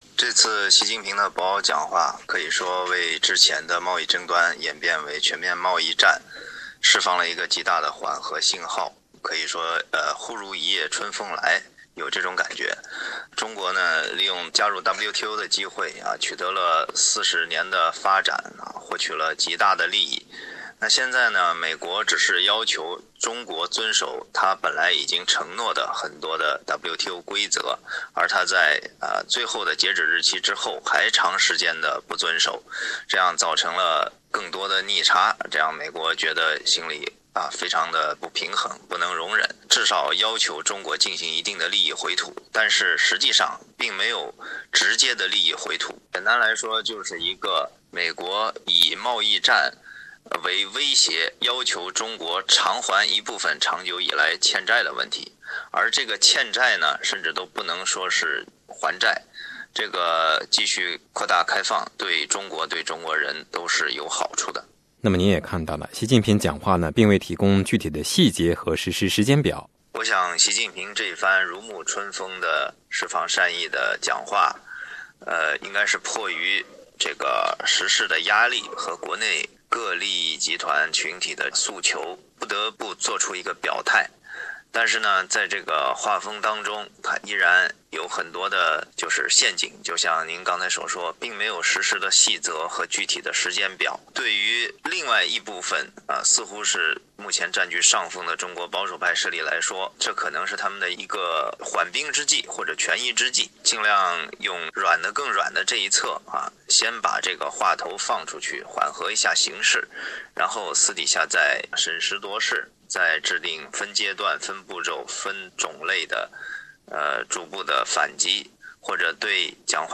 详细采访内容，请您点击语音收听。